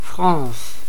Ääntäminen
Synonyymit roi Ääntäminen France: IPA: [fʁɒ̃ːs] Quebec: IPA: [la fʁãːs] Tuntematon aksentti: IPA: /fʁɑ̃s/ IPA: /ˈfrɑ̃s/ Haettu sana löytyi näillä lähdekielillä: ranska Käännös 1.